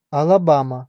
Ääntäminen
Ääntäminen US US : IPA : /ˌæ.lə.ˈbæ.mə/ Lyhenteet ja supistumat AL Ala.